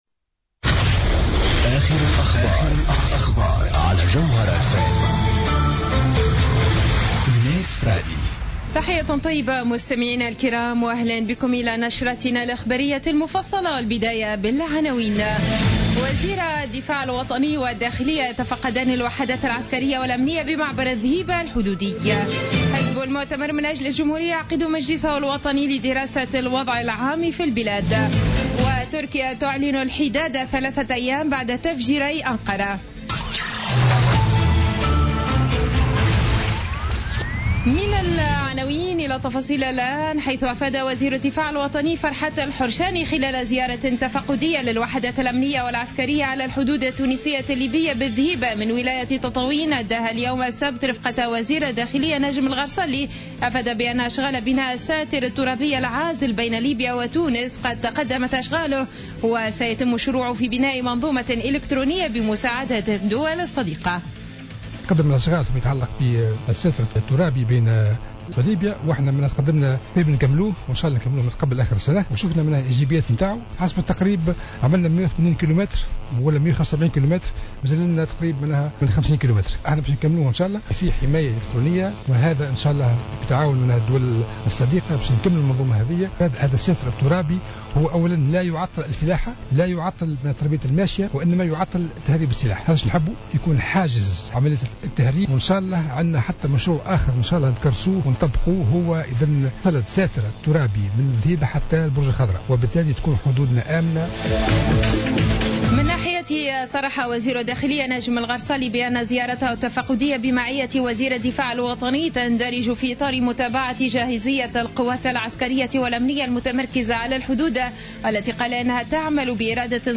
نشرة أخبار السابعة مساء ليوم السبت 10 أكتوبر 2015